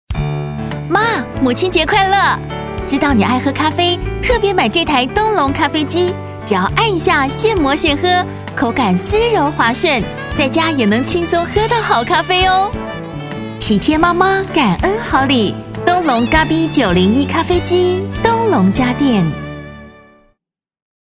台語配音 國語配音 女性配音員